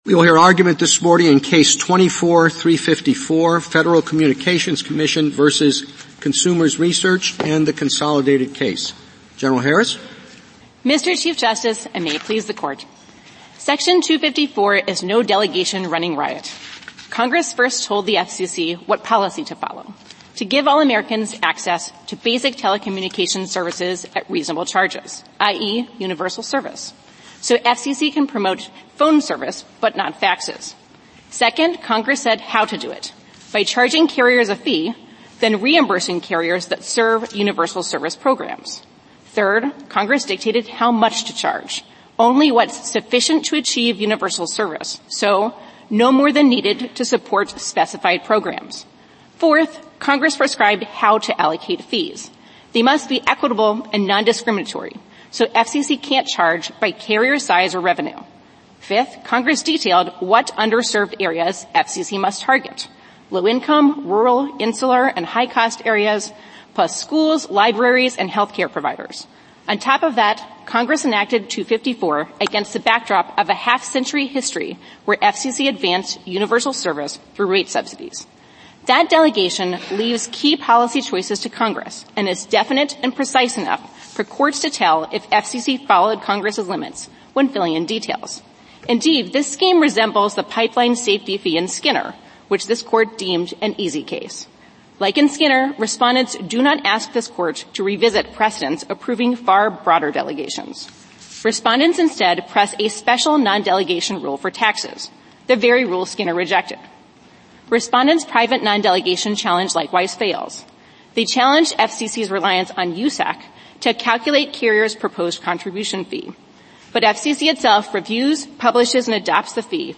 U.S. Supreme Court Oral Arguments